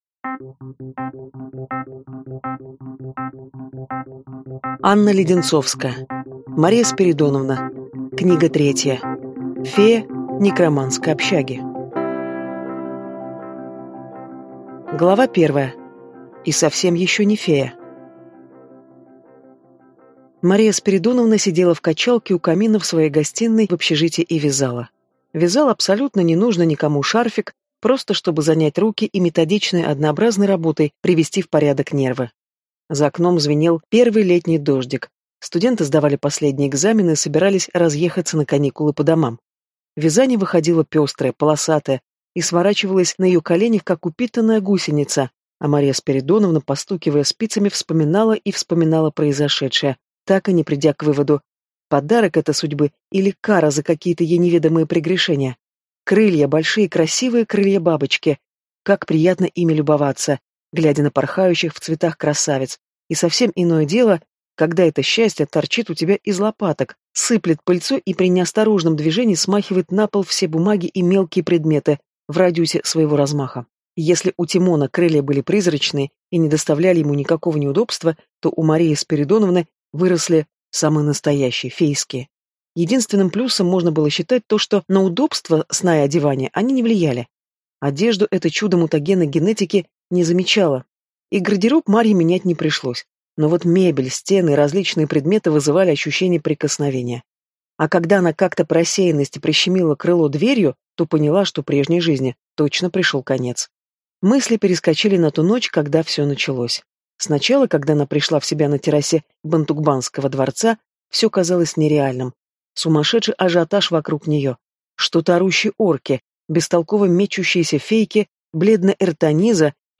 Аудиокнига Мария Спиридоновна. Книга 3. Фея некромантской общаги | Библиотека аудиокниг